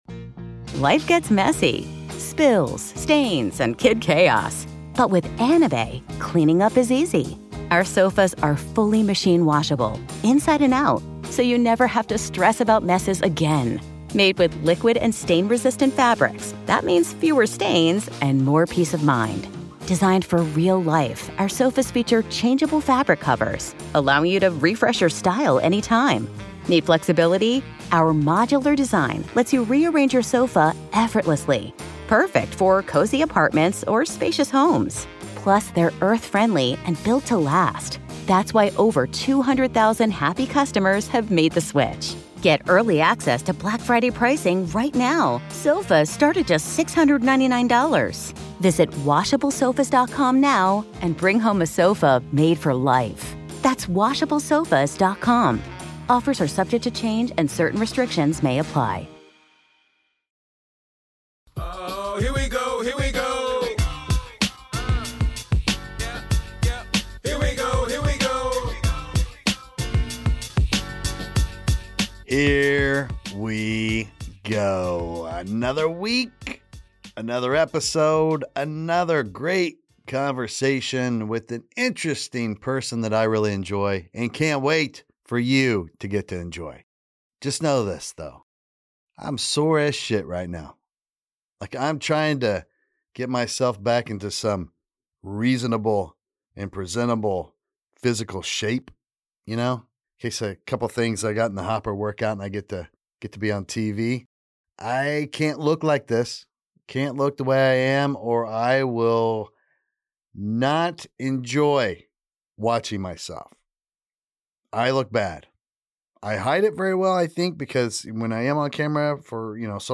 So, he's going to interview every person on the planet. That's one guest each week for the next 8 billion weeks.